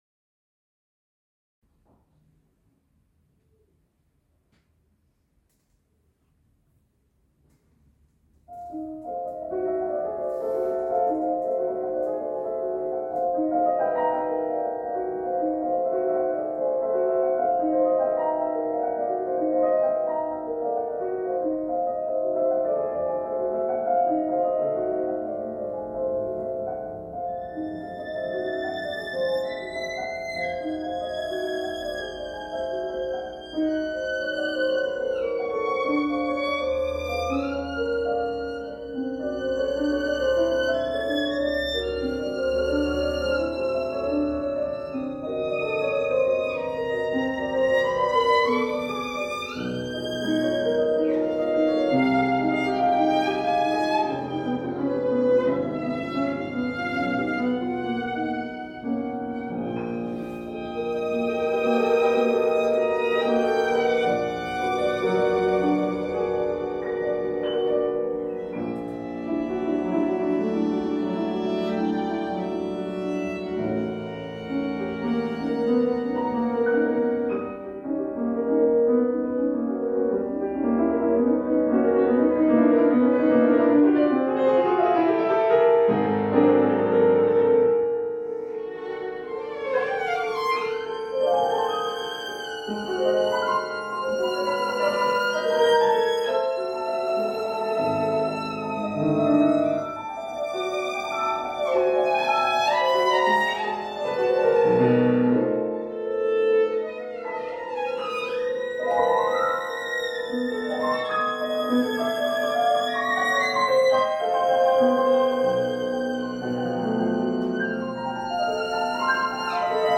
fortepian
skrzypce) w koncercie kameralnym "Classical Spring Concert", który odbył się w Mozarthaus Vienna (Wiedeń, Austria).
Wydarzenie zgromadziło tłumy słuchaczy, którzy nagrodzili artystki wielkimi brawami.